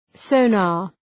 {‘səʋnɑ:r}